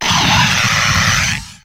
Grito de Toucannon.ogg
Grito_de_Toucannon.ogg.mp3